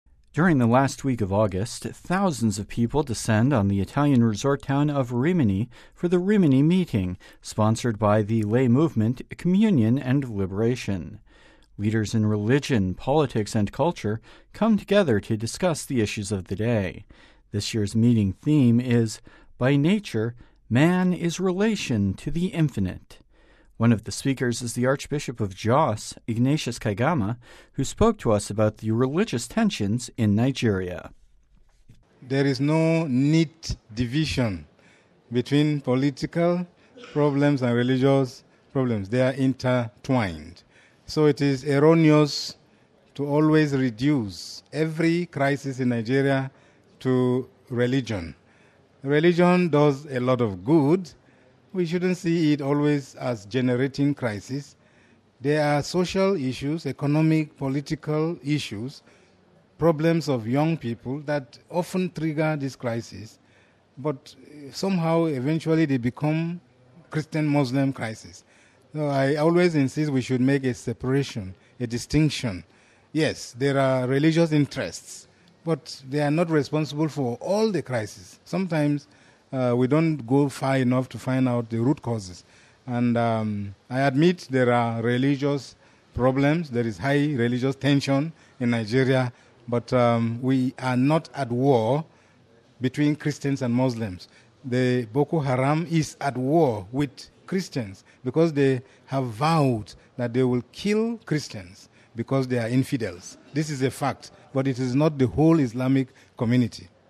One of the speakers is the Archbishop of Jos, Ignatius Kaigama, who spoke to us about the current spate of violence in Nigeria.